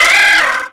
Cri d'Osselait dans Pokémon X et Y.